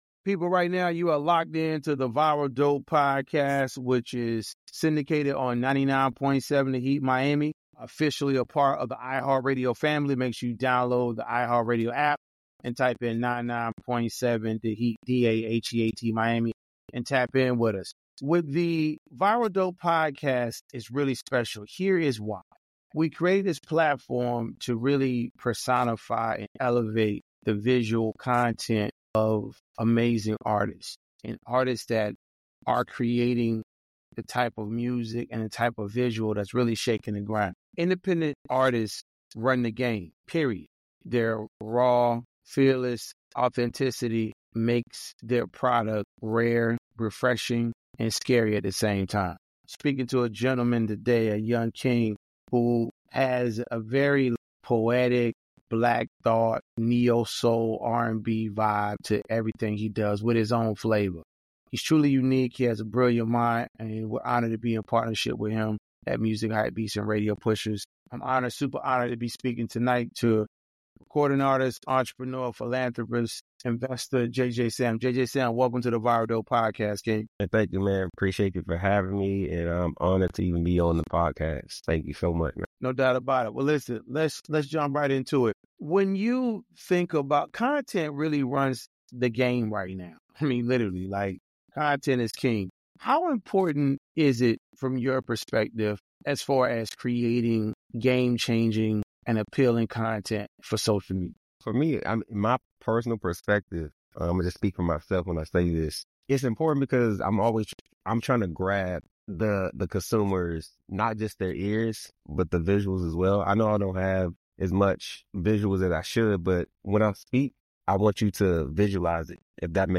Be a guest on this podcast Language: en Genres: Entertainment News , Music , Music Interviews , News Contact email: Get it Feed URL: Get it iTunes ID: Get it Get all podcast data Listen Now...